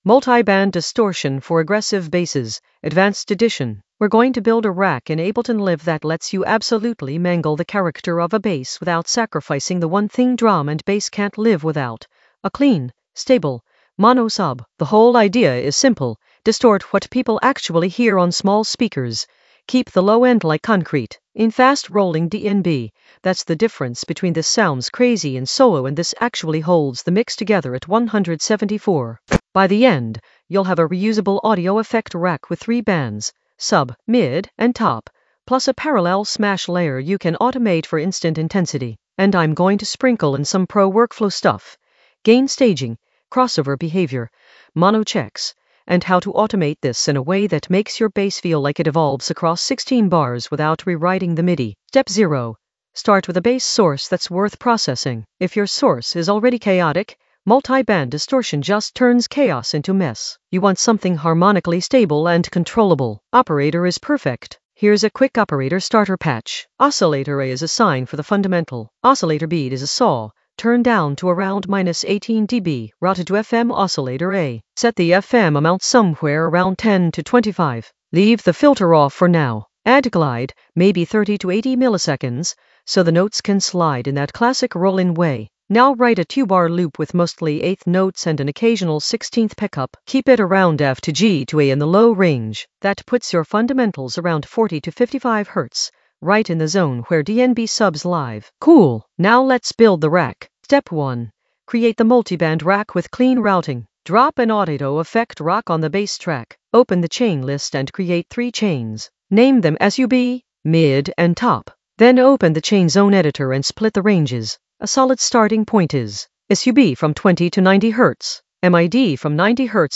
An AI-generated advanced Ableton lesson focused on Multi-band distortion for aggressive basses in the Sound Design area of drum and bass production.
Narrated lesson audio
The voice track includes the tutorial plus extra teacher commentary.